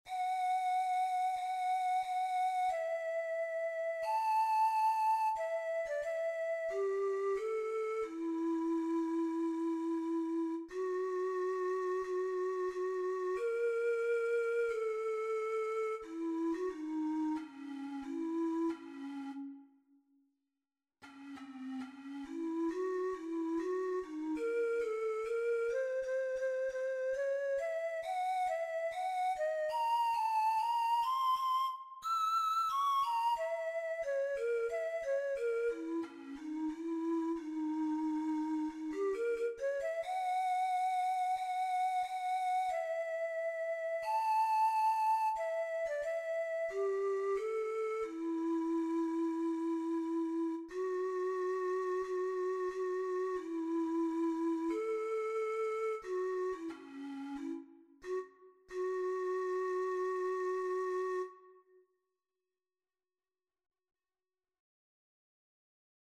Middle Eastern European Scale B
5-pan-flute-audio.mp3